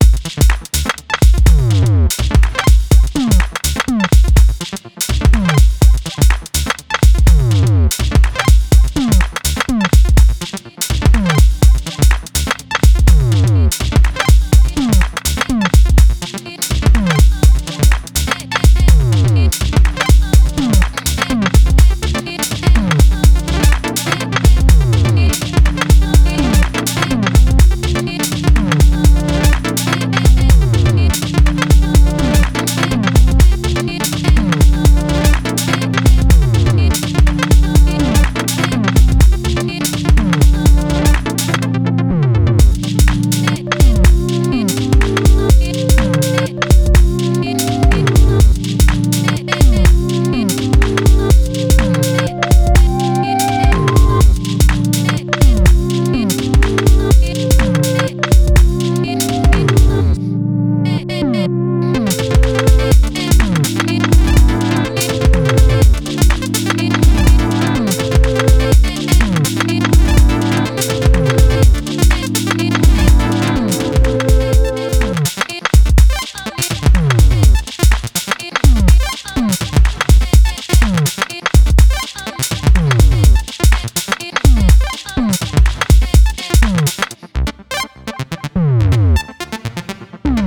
House Techno Acid